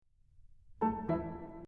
I have to apologize for the recording here, I haven’t gotten to the studio yet. So this is actually recorded at home, in my practicing studio. And as you can hear, I have practiced some, the piano is very out of tune.